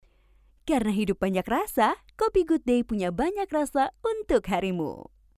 Female
Teenager (13-17), Adult (30-50)
My voice is sounds like wise woman,warm, smart, confident, and pleasant
Television Spots